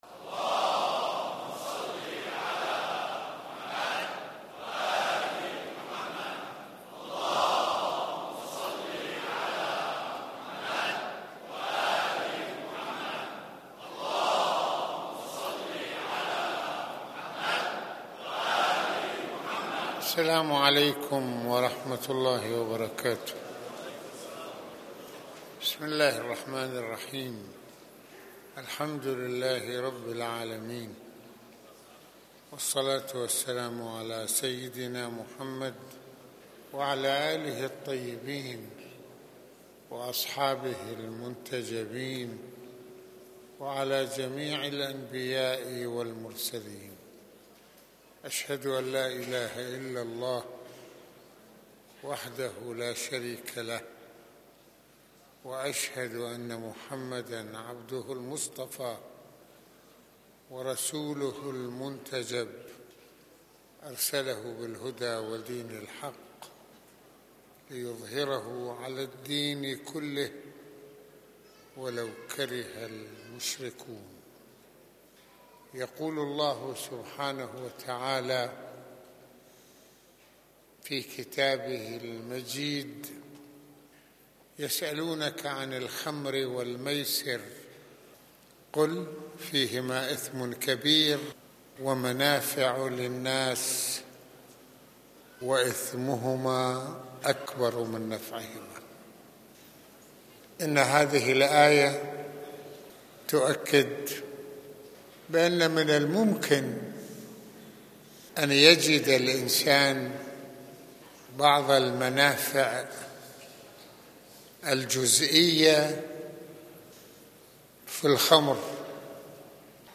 المناسبة : خطبة الجمعة المكان : مسجد الحسنين (ع)